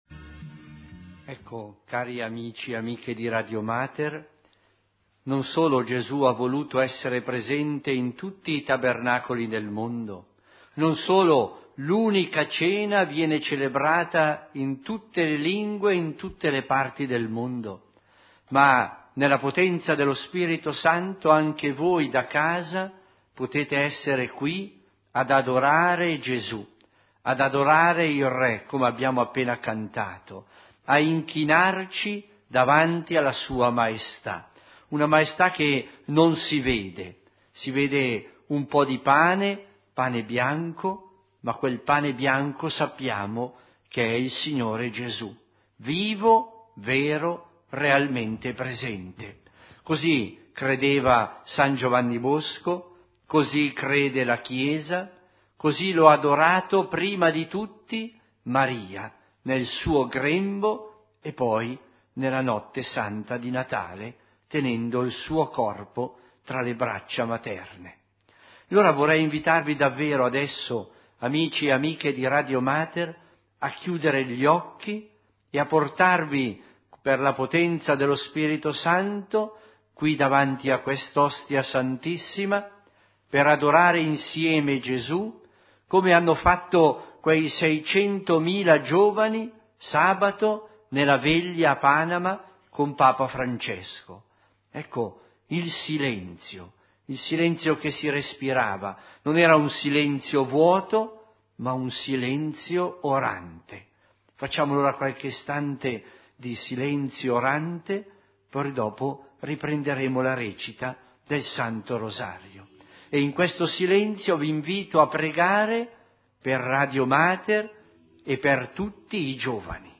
Adorazione a Gesù eucaristico